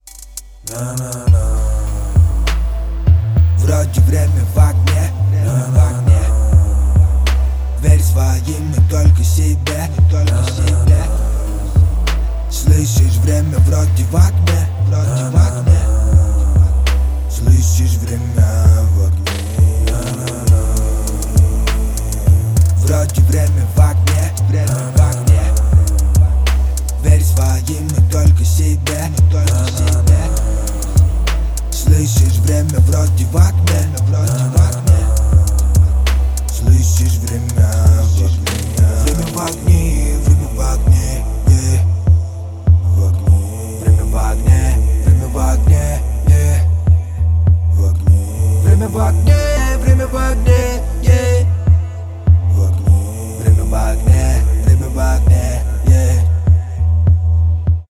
• Качество: 128, Stereo
русский рэп